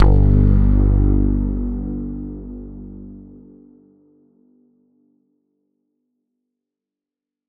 Bass_G_03.wav